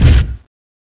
bombrelease.mp3